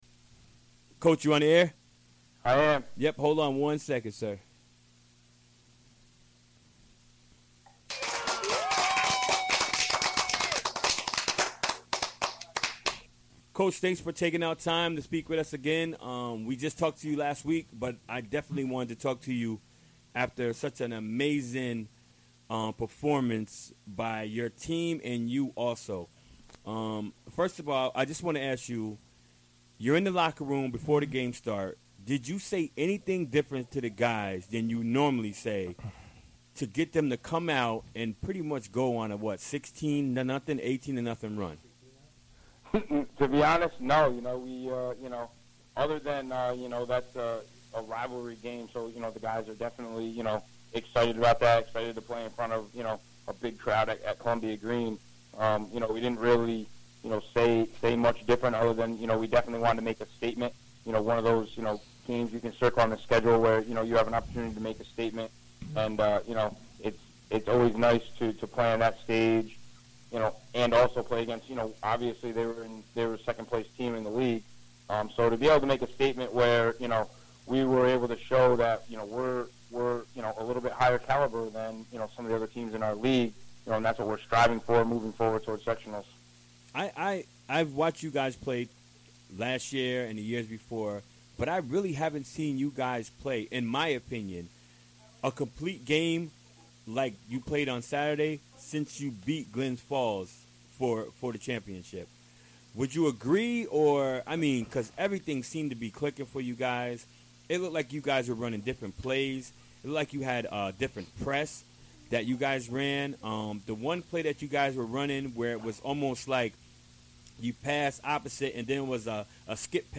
Recorded during the WGXC Afternoon Show Wednesday, February 1, 2017.